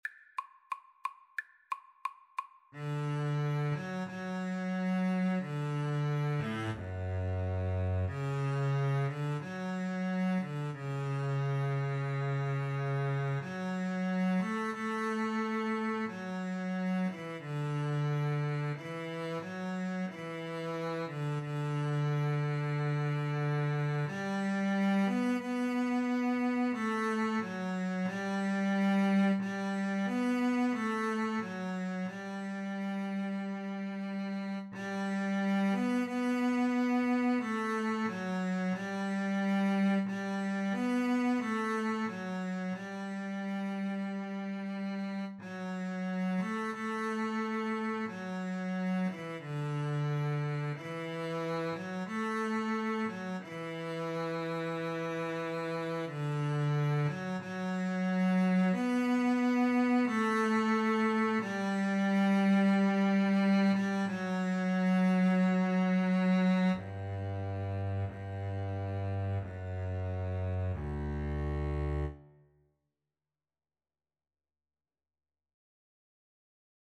CelloDouble BassDouble Bass (Bass Clef)
=180 Largo
Classical (View more Classical Cello-Bass Duet Music)